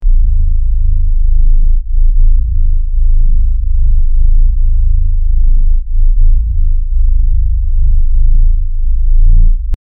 futuristic bass machinry start button for a game title screen
futuristic-bass-machinry--frrxgek3.wav